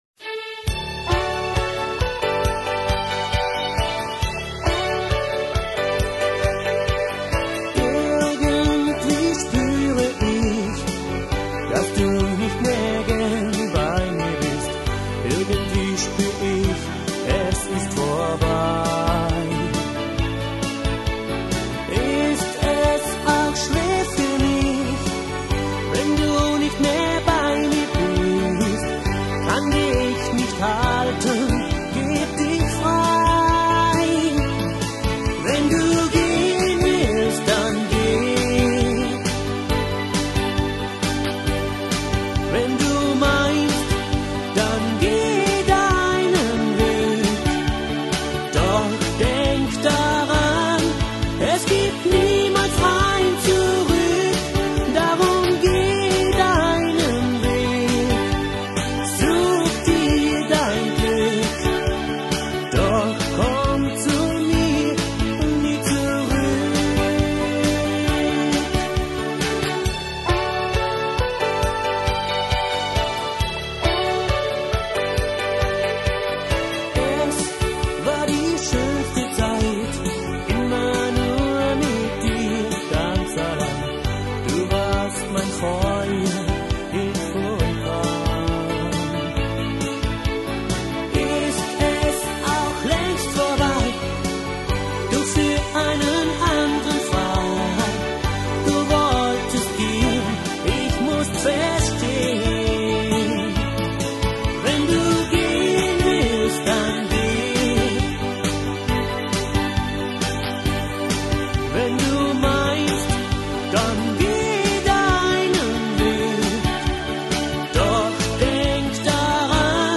He is a great singer and he also writes his own music.